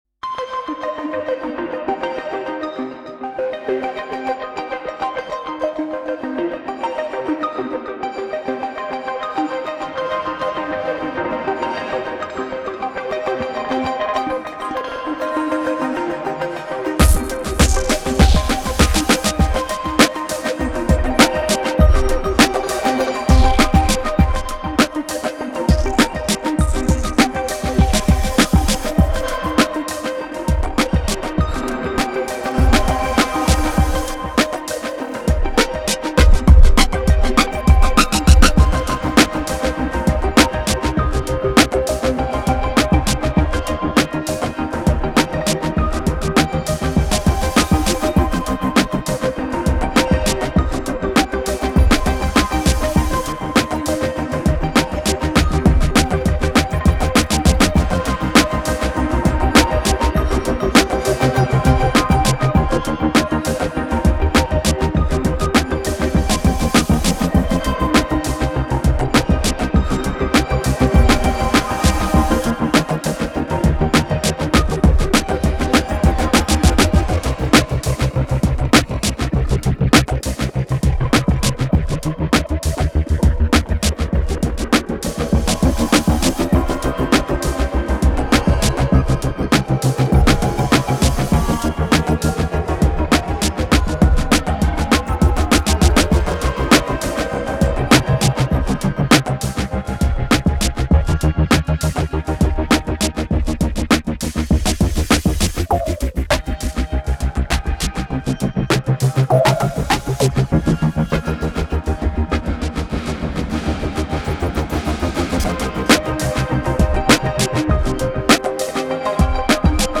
I think it’s also the first track I’ve heard that has used acoustic drum samples in a way I’d expect to hear on the DT.
Nothing wrong with that, but I do love a bit of downtempo acoustic drumming.